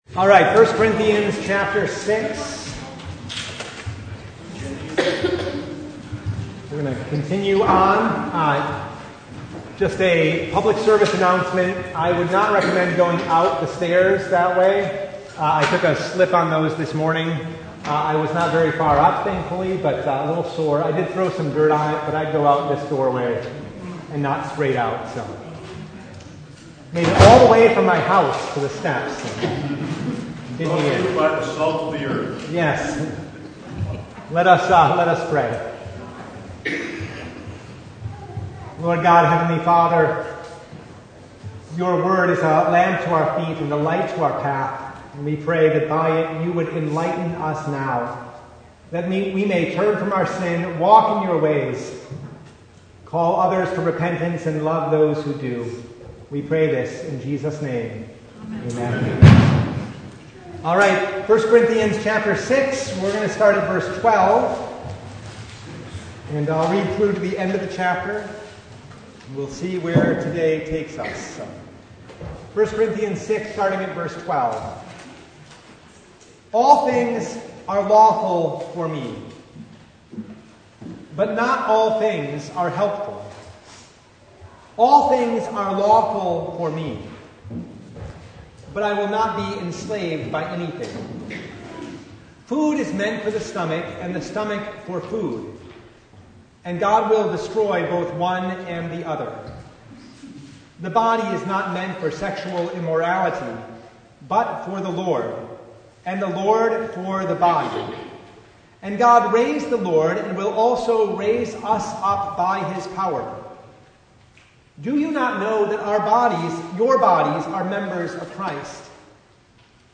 1 Corinthians 6:9-20 Service Type: Bible Hour Topics: Bible Study